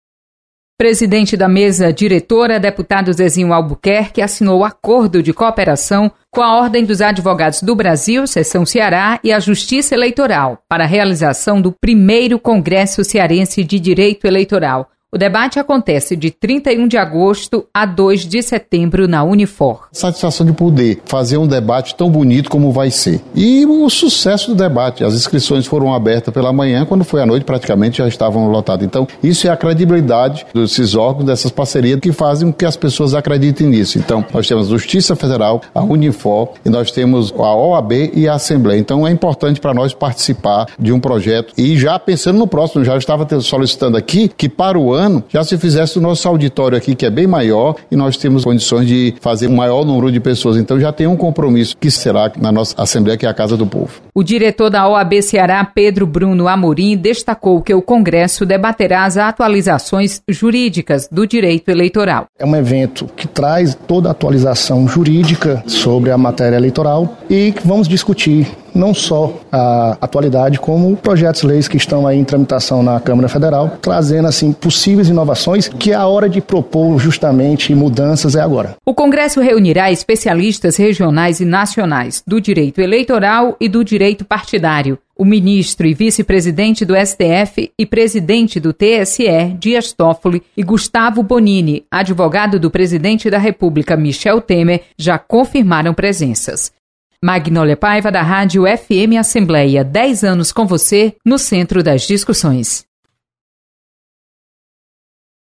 Assembleia assina acordo para realização de Congresso de Direito Eleitoral. Repórter